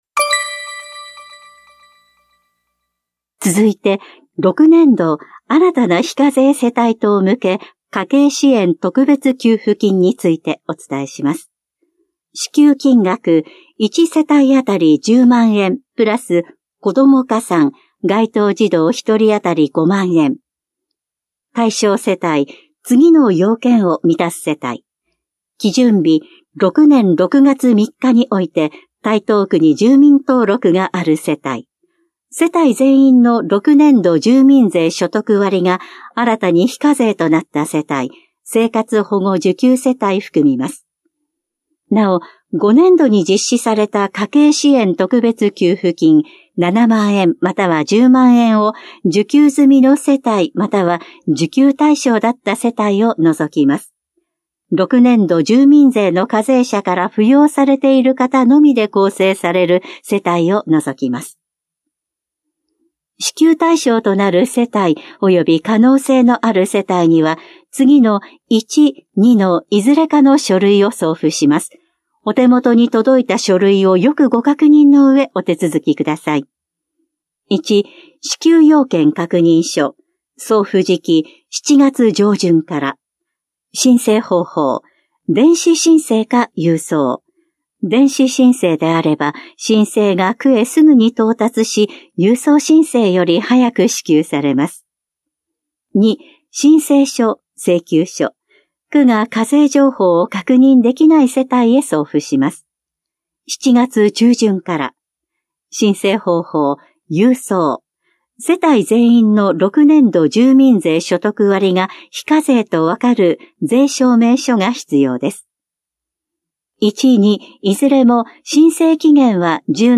広報「たいとう」令和6年7月5日号の音声読み上げデータです。